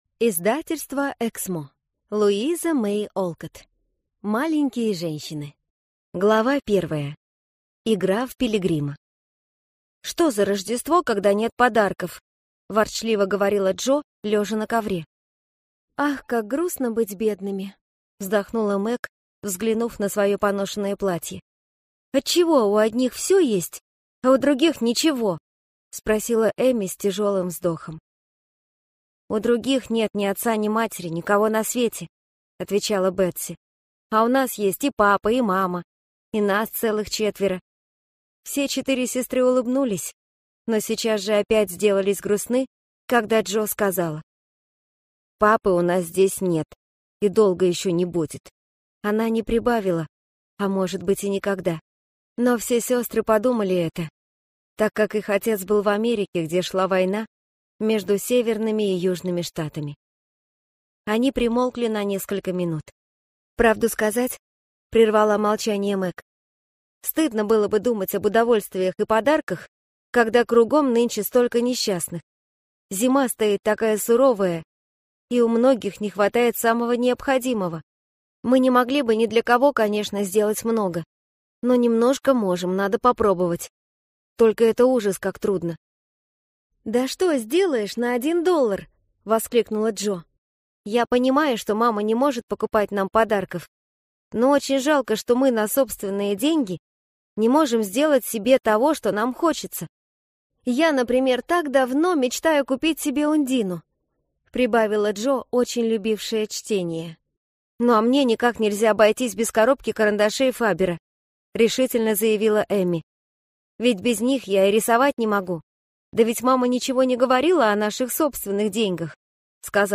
Аудиокнига Маленькие женщины | Библиотека аудиокниг